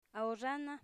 Phonological Representation ao'ʐana